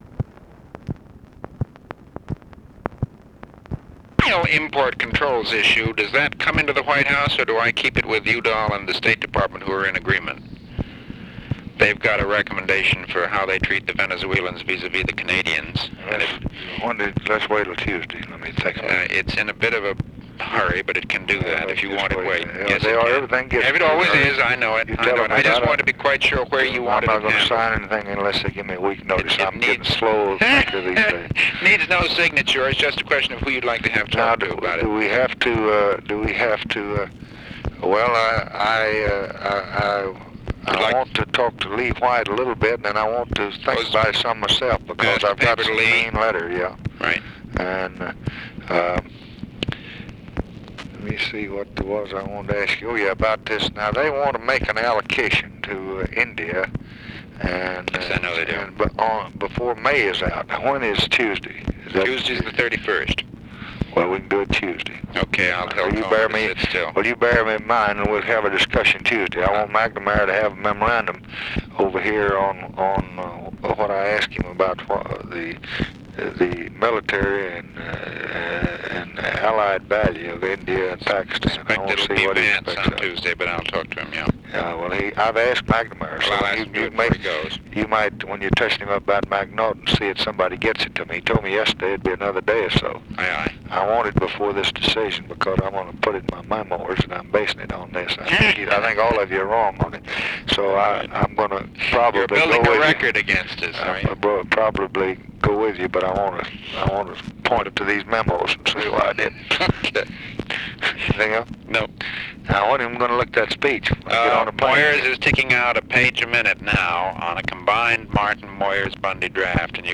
Conversation with MCGEORGE BUNDY, May 27, 1965
Secret White House Tapes